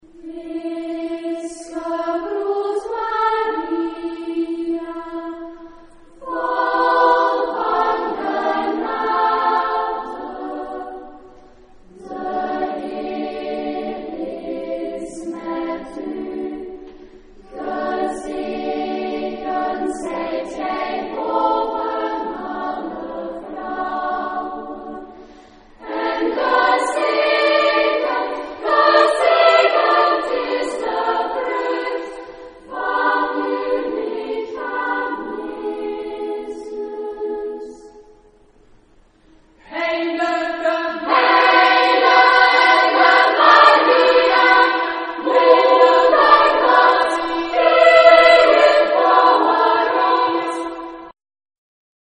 Pronunciation.